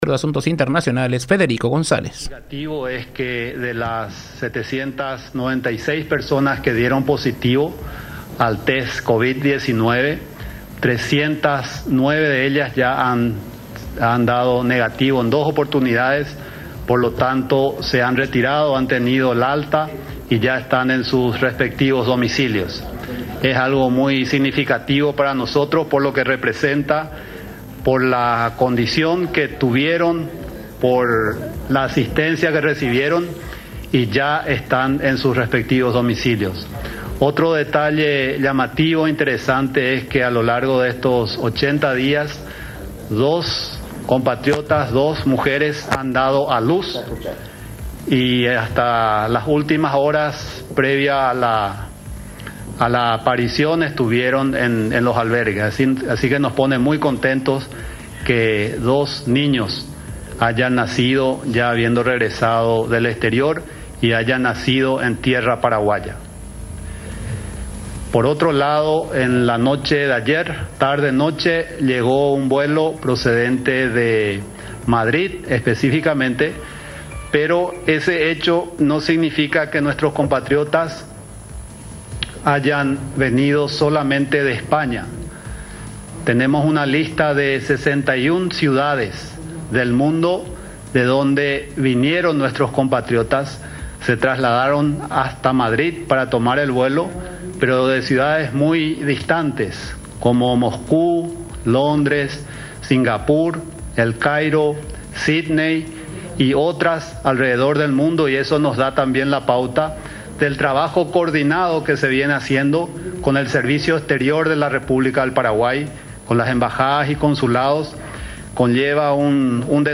23-COMFERENCIA-DE-FEDERICO-GONZALEZ.mp3